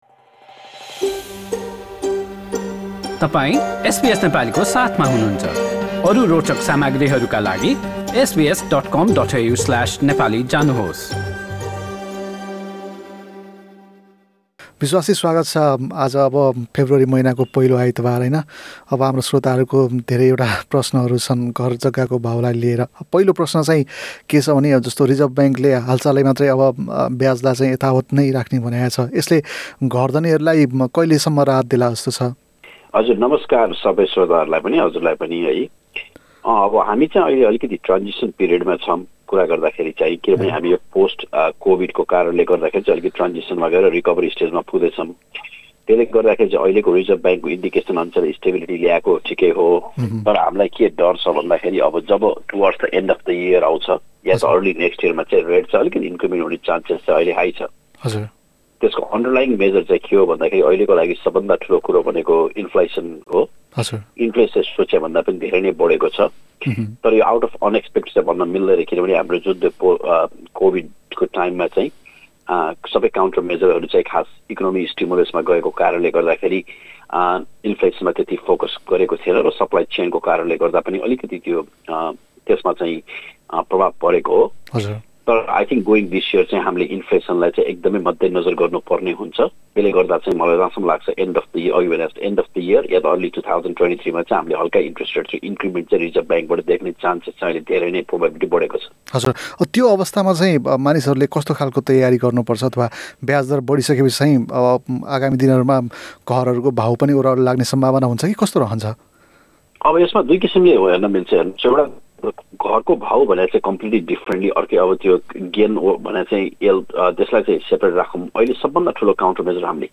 आर्थिक कुराकानी हरेक महिनाको पहिलो आइतवार अपराह्न ४ बजेको कार्यक्रममा प्रत्यक्ष प्रसारण हुन्छ।